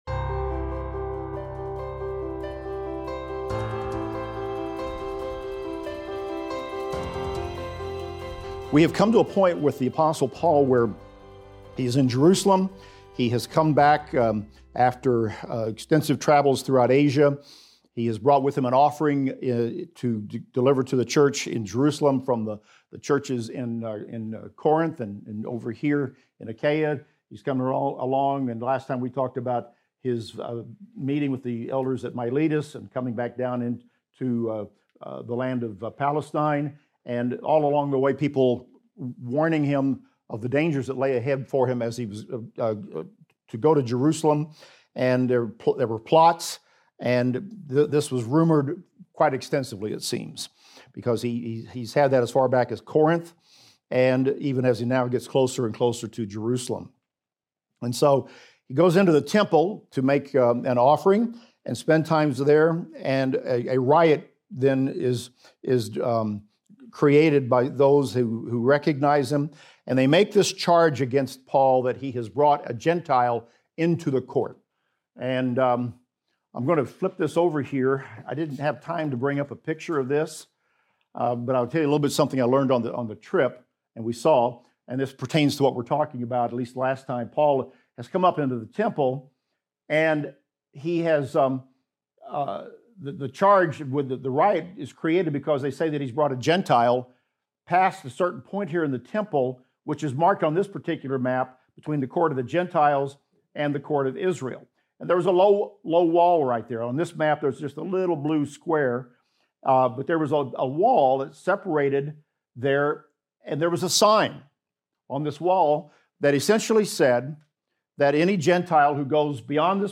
In this class we will discuss Act 23:11-35 thru Acts 24:1-24 and examine the following: The Lord appears to Paul in a vision, assuring him that he will testify in Rome.